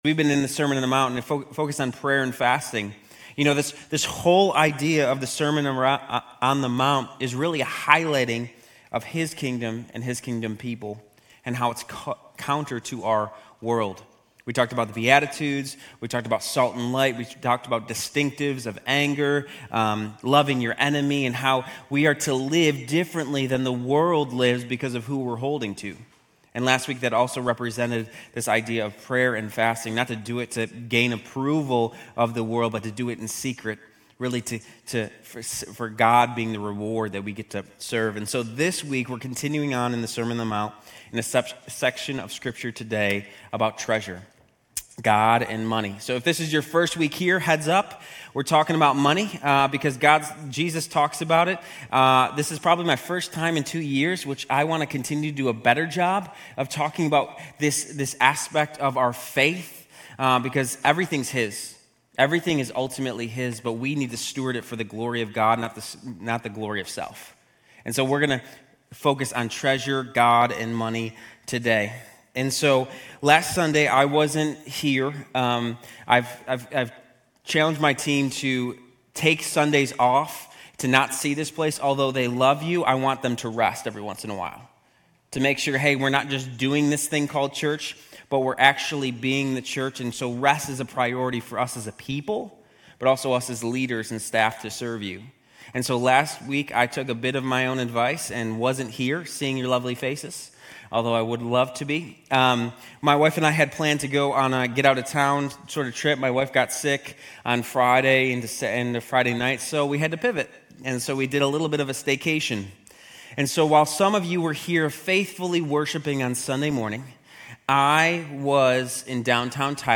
Grace Community Church University Blvd Campus Sermons 3_16 University Blvd Campus Mar 16 2025 | 00:29:36 1x 00:00 / 00:29:36 Subscribe Share RSS Feed Share Link Embed